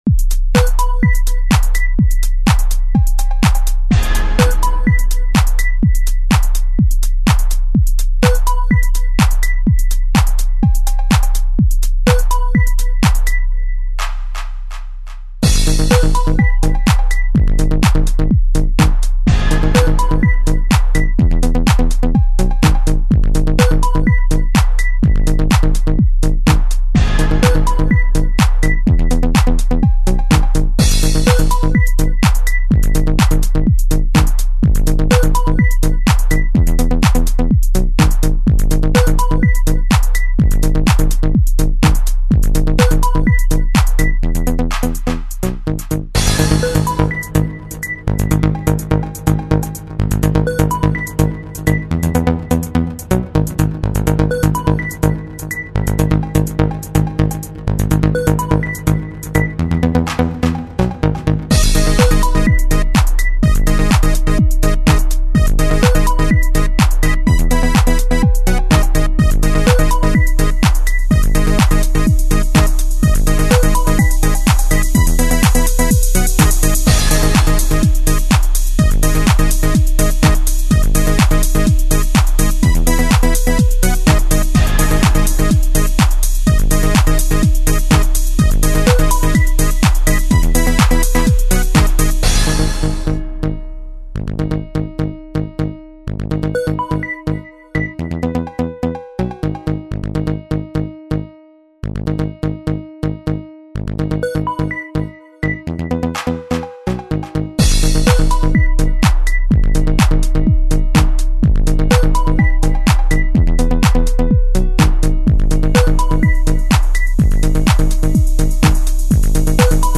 32000Hz Joint Stereo
stylе: Electro House